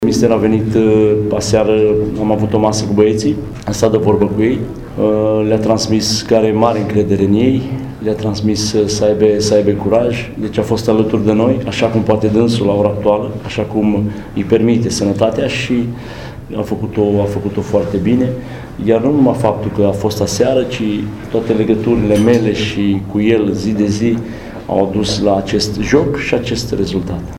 a spus la conferința de presă